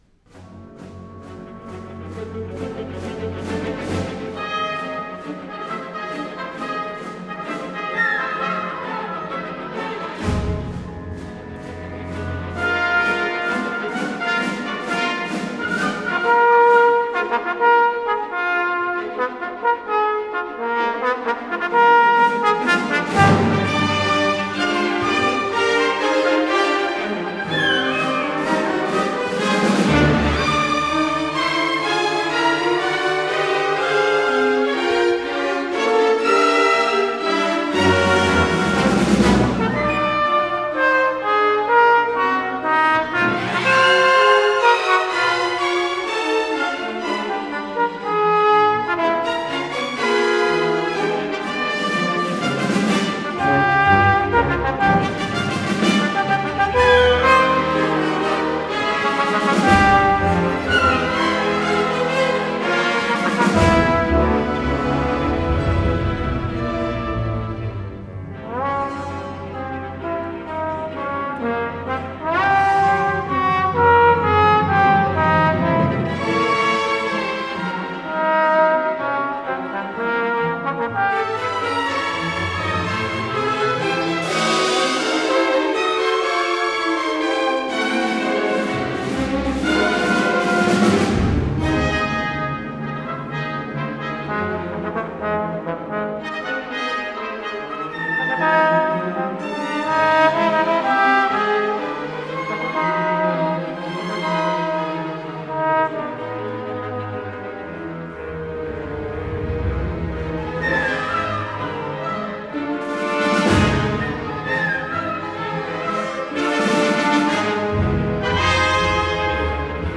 Bloch: Symphony for Trombone and Orchestra - II. Agitato